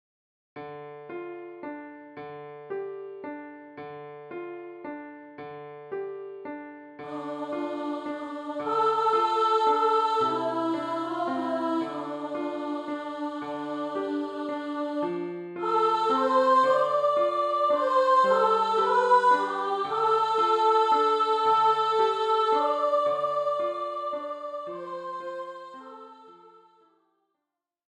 Instrumente